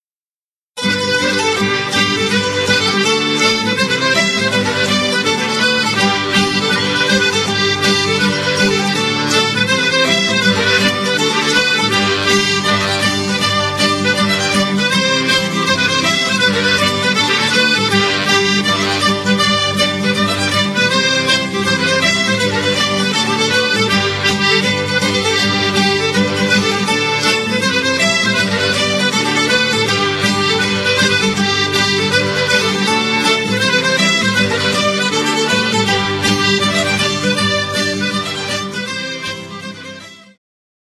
skrzypce
akordeon, flety proste, whistles, cytra
mandolina, gitara akustyczna